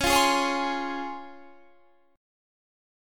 DbM7 Chord
Listen to DbM7 strummed